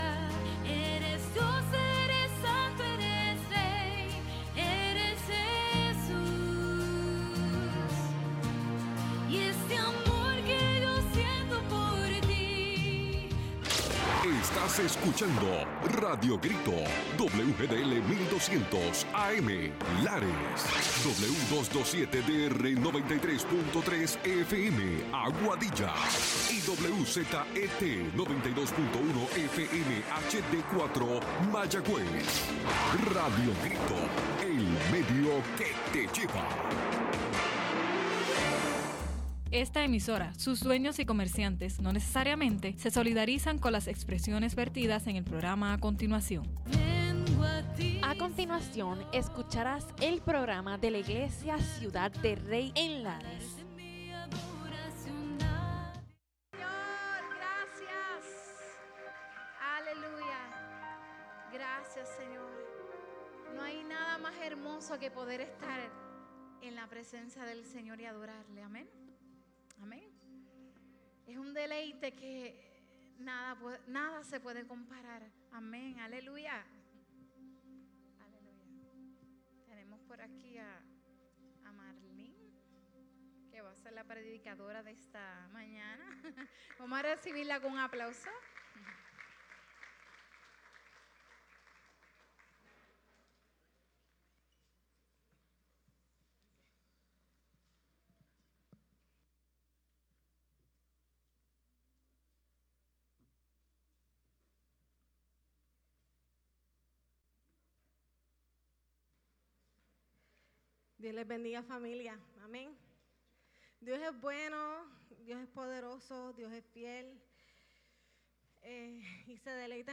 Los hermanos de Ciudad del Rey nos traen un programa especial de su servicio en la iglesia.